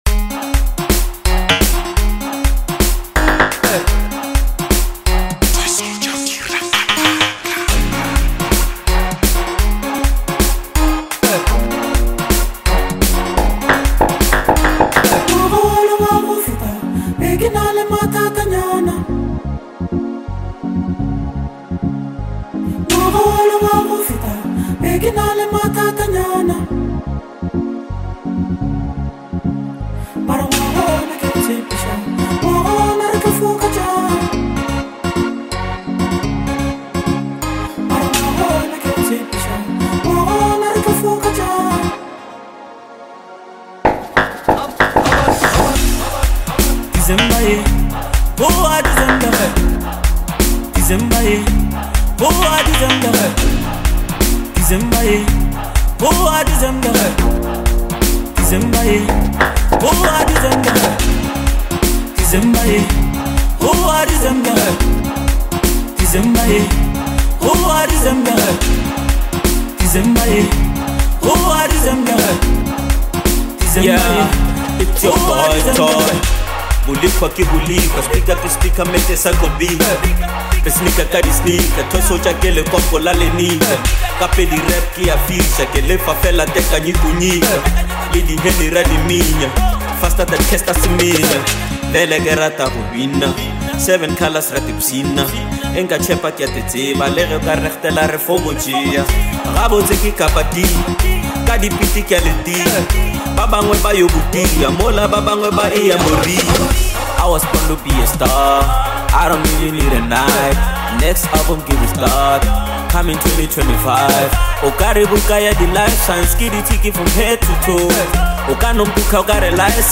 energetic track